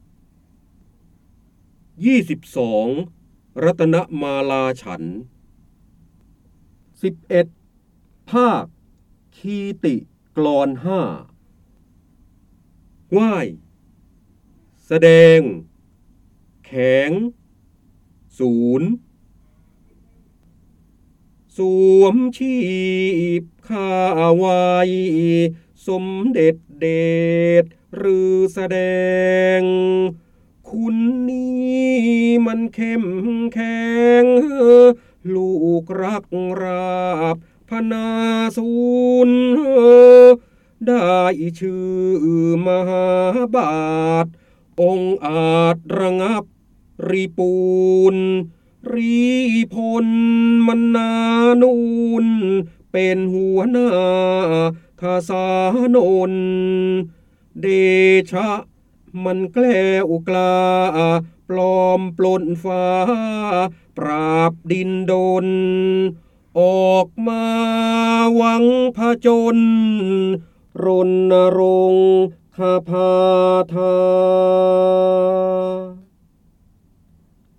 เสียงบรรยายจากหนังสือ จินดามณี (พระโหราธิบดี) ๒๒ รัตนมาลาฉันท์ ๑๑ พากยคีติกลอน
คำสำคัญ : ร้อยกรอง, พระโหราธิบดี, ร้อยแก้ว, จินดามณี, การอ่านออกเสียง, พระเจ้าบรมโกศ
ลักษณะของสื่อ :   คลิปเสียง, คลิปการเรียนรู้